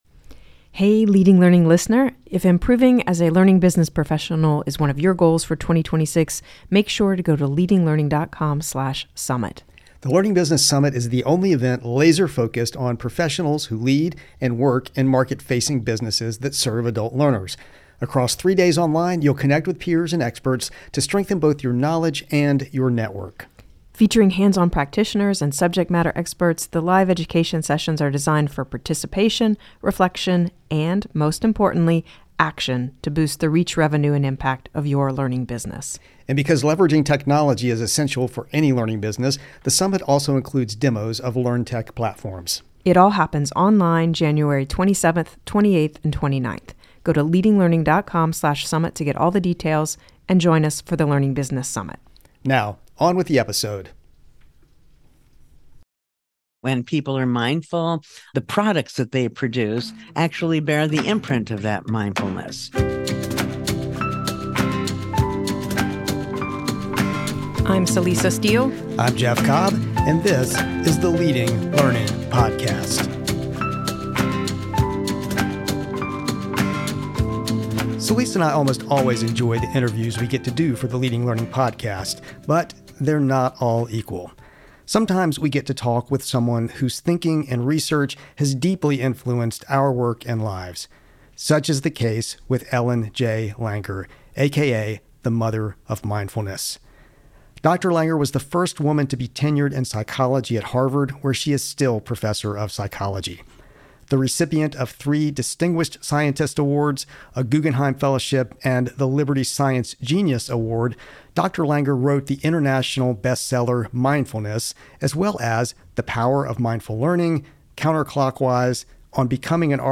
Leading Learning Podcast interviewee Dr. Ellen Langer On the Leading Learning Podcast, sometimes we have the opportunity to talk with someone whose thinking and research have deeply influenced our work and lives.